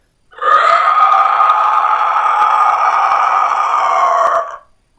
僵尸叫声" 长长的咆哮声
描述：长僵尸咆哮/尖叫
Tag: 怪物 怪物 召唤 僵尸 发出刺耳的声音 科幻 低吼